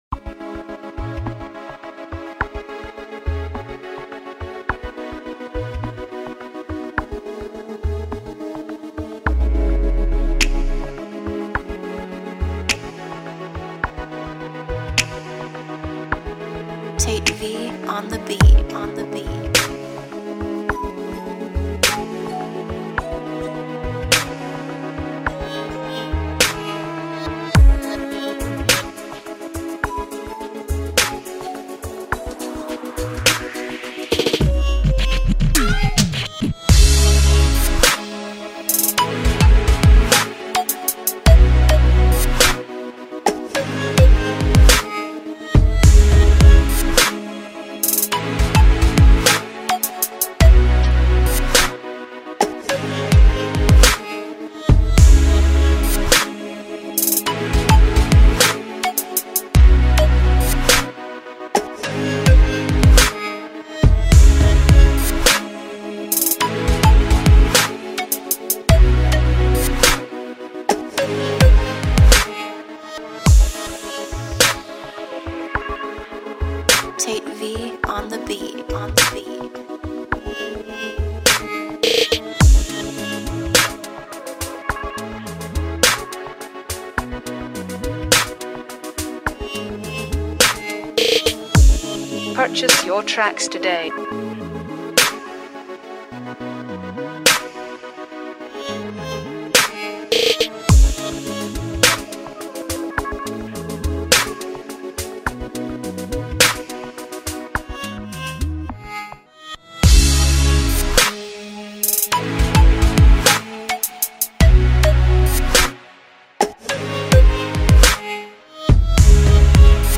Synth, Drum, Heavy Bass, Piano, 808, Percussion